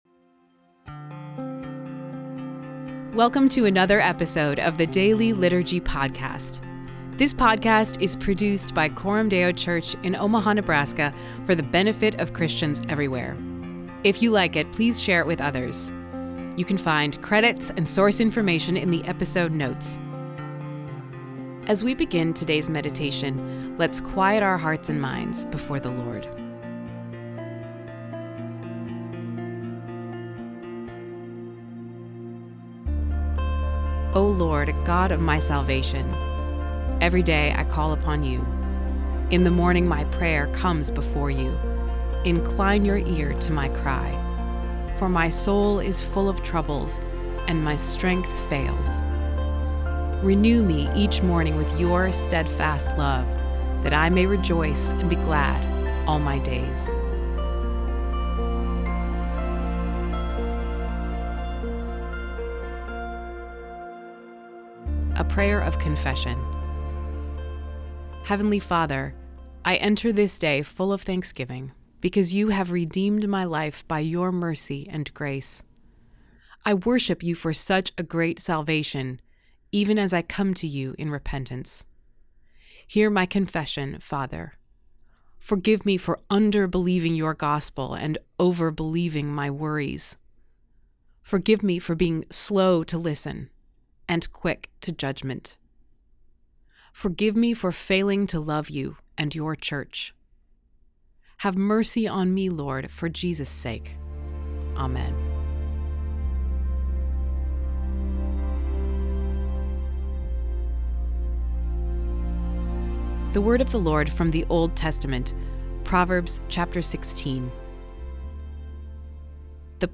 The Daily Liturgy Podcast provides Christians everywhere with a historically informed, biblically rich daily devotional in audio format.